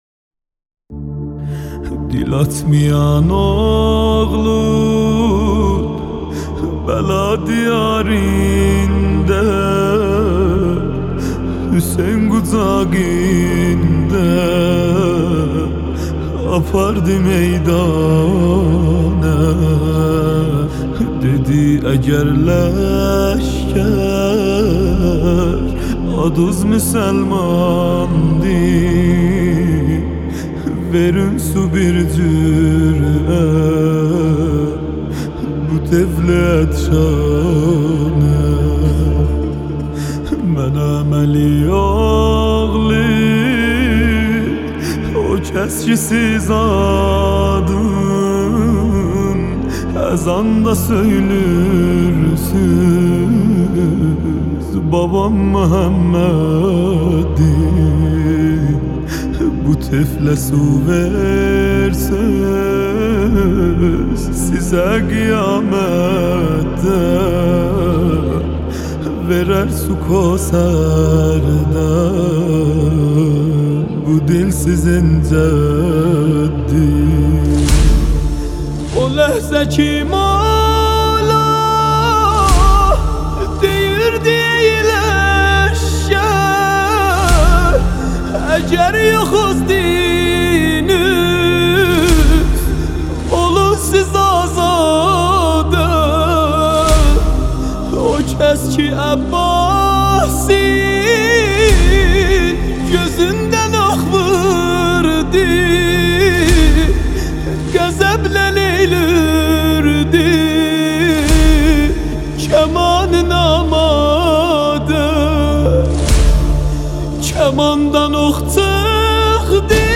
دانلود مداحی ترکی